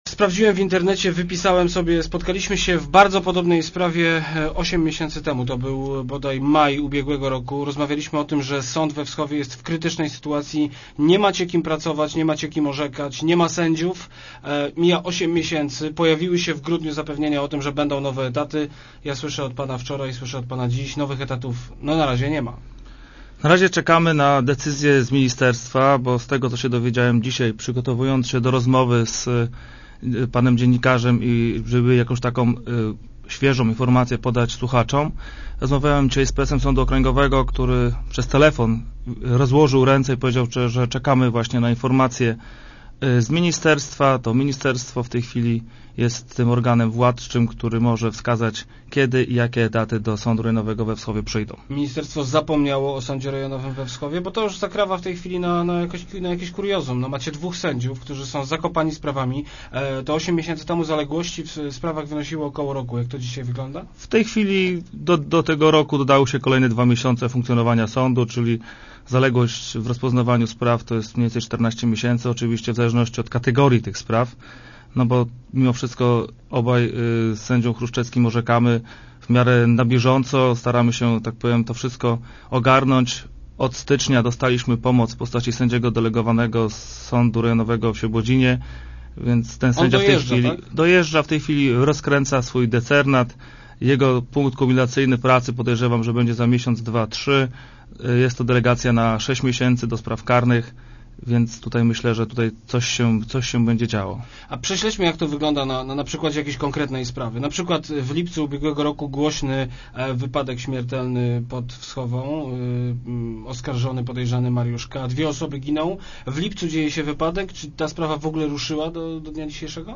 Odchodzę z zawodu – ogłosił dziś w Rozmowach Elki prezes Sądu Rejonowego we Wschowie, Jarosław Sielecki. Zdaniem sędziego powodem odejścia jest trwający już prawie rok paraliż sądu, w którym orzeka tylko dwóch sędziów i od miesiąca oddelegowany pomocnik.